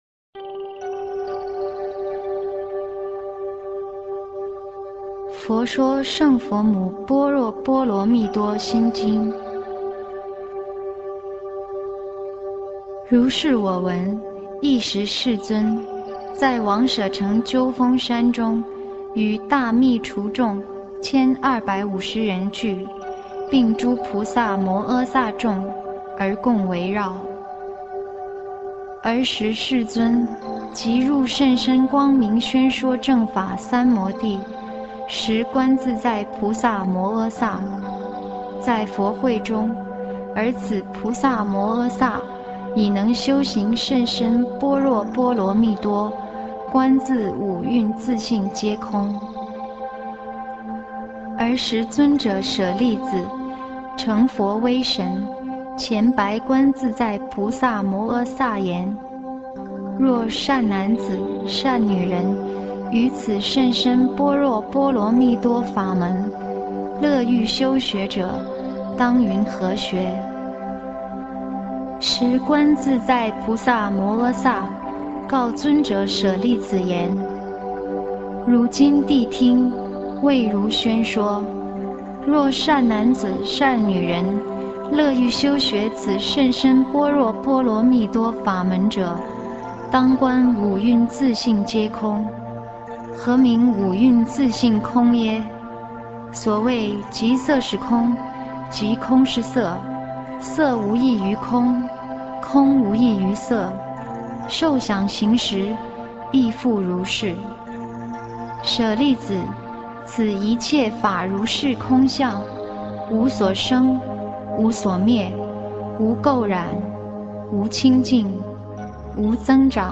佛曲音樂 > 經典唱誦/法會佛事